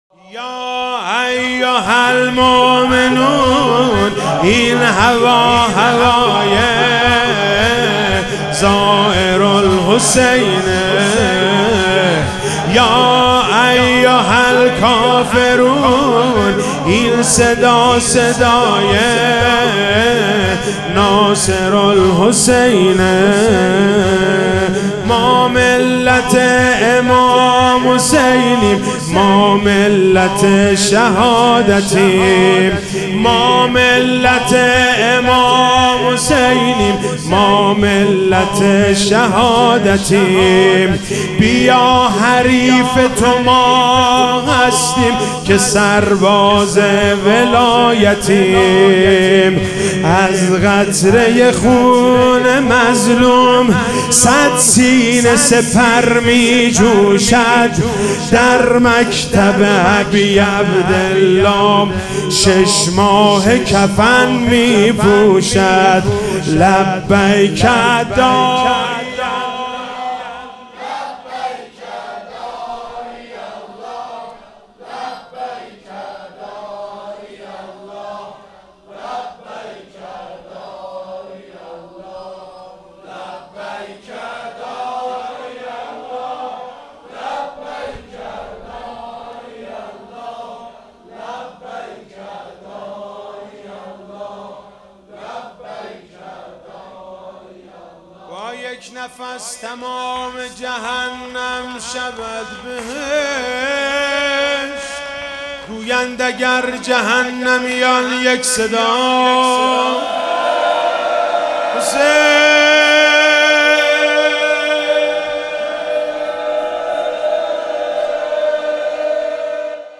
محرم99 - شب هفتم - شور - یا ایها المومنون این هوا